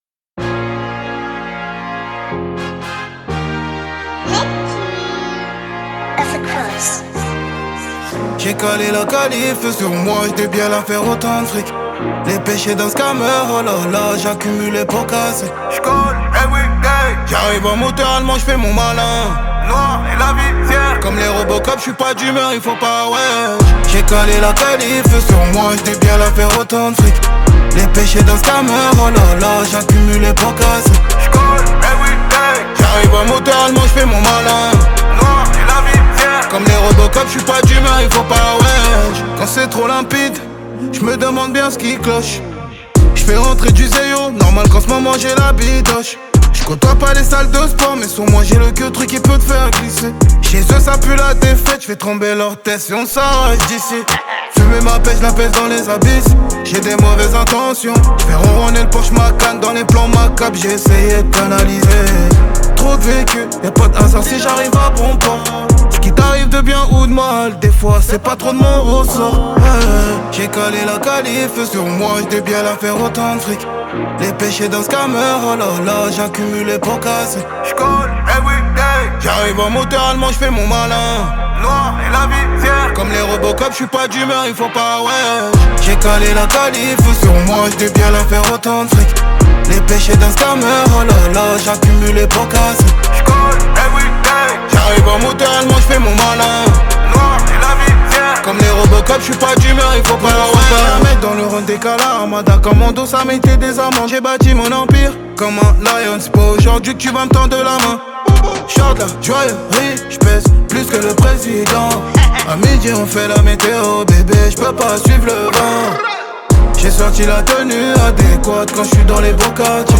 Genres : french rap, pop urbaine